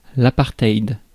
Ääntäminen
Synonyymit séparation ségrégation ségrégationnisme Ääntäminen France: IPA: /a.paʁ.tɛjd/ Haettu sana löytyi näillä lähdekielillä: ranska Käännös 1. апартейд {m} (apartejd) Suku: m .